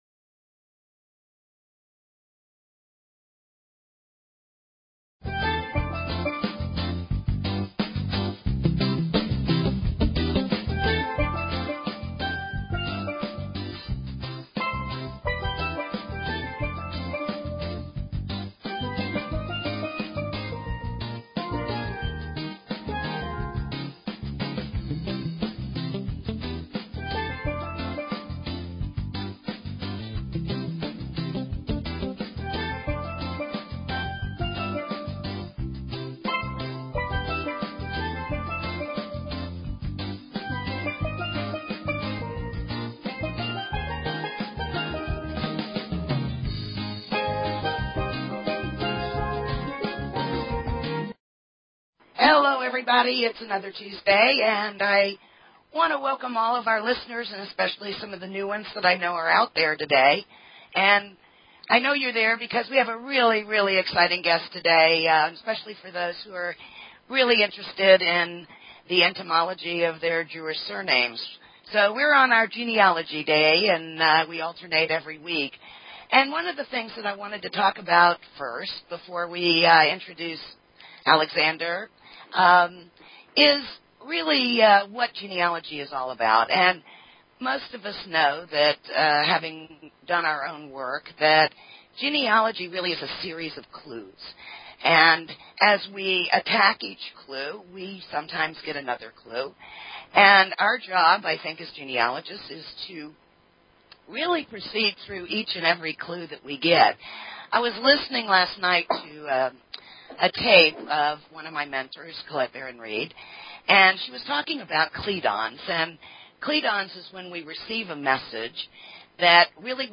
Talk Show Episode, Audio Podcast, Where_Genealogy_and_Spirit_Connect and Courtesy of BBS Radio on , show guests , about , categorized as